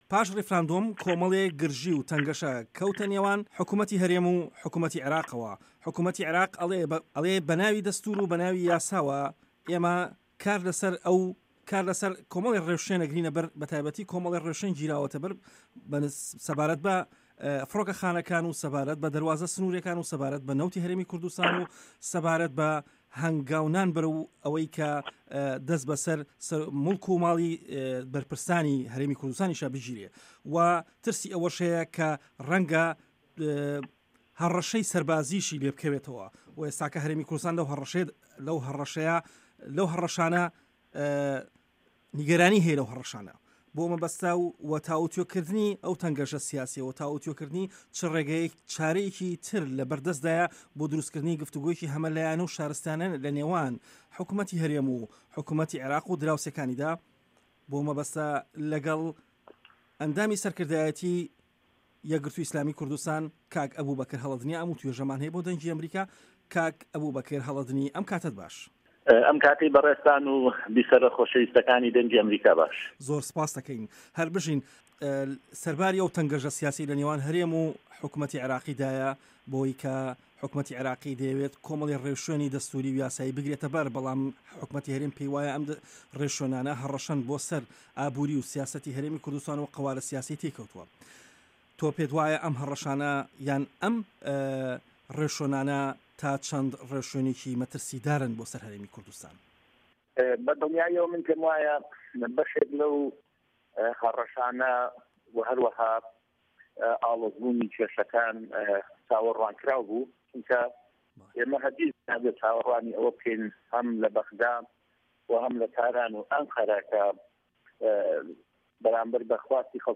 وتووێژ لەگەڵ ئەبوبەکر هەڵەدنی